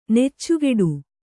♪ neccugeḍu